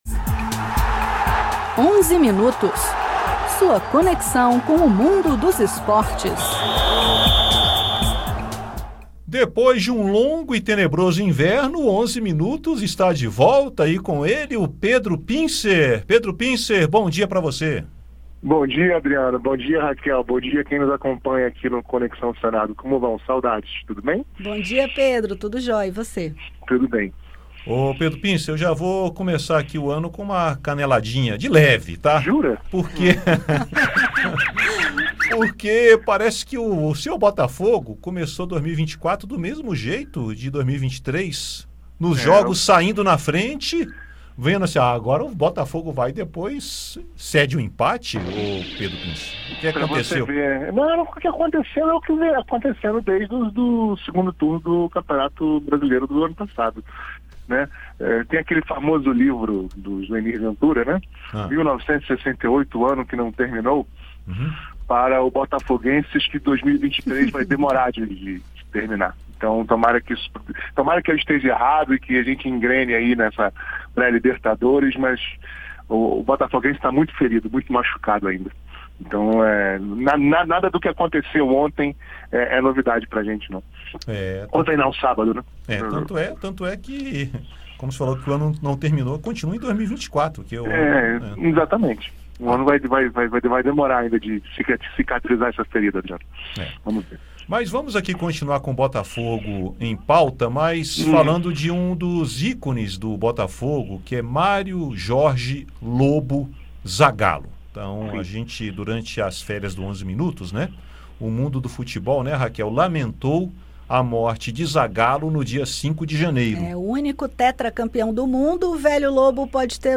Rádio Senado